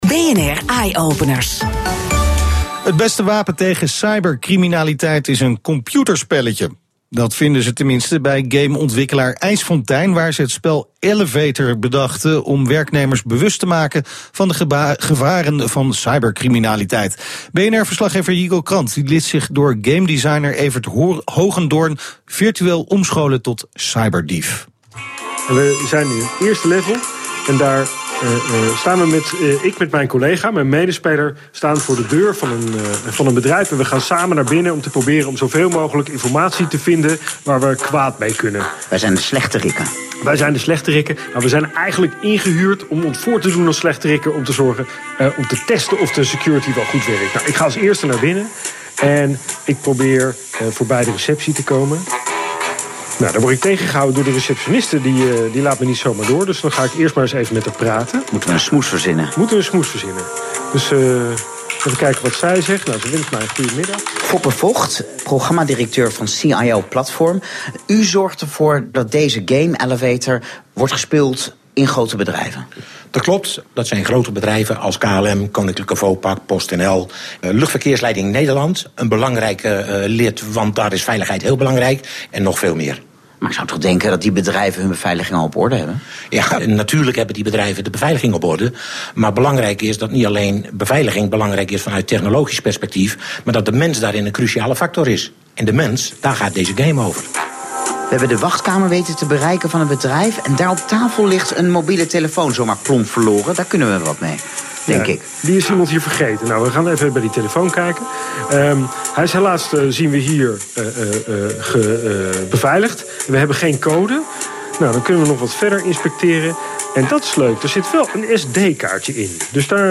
Aandacht voor de Elevator Game bij Eyeopeners op BNR Radio. Elke dinsdag om 15.00 uur is op BNR Radio het programma Eyeopeners te horen.